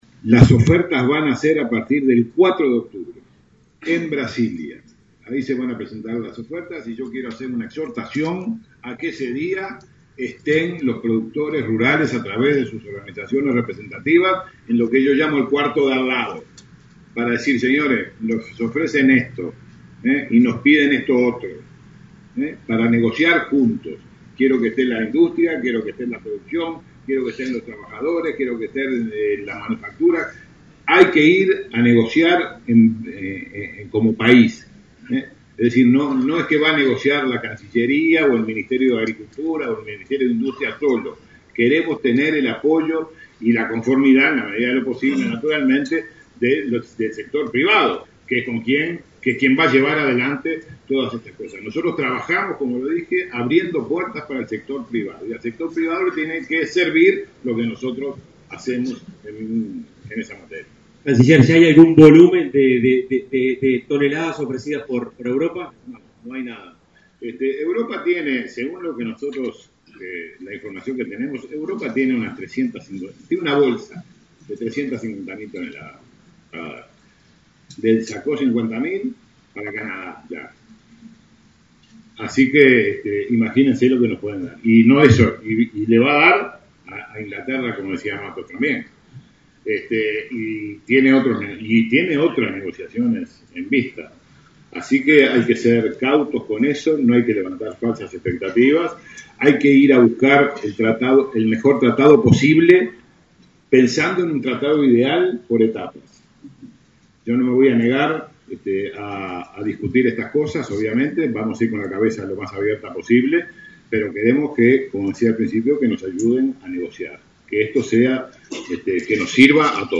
El 4 de octubre en Brasilia se reunirán nuevamente representantes del Mercosur y de la Unión Europea para avanzar hacia un acuerdo comercial. El canciller Rodolfo Nin Novoa, en su disertación sobre mercados cárnicos en la Expo Prado, instó a productores, empresarios y trabajadores a que “presenten ofertas en la sala de al lado” para negociar como país.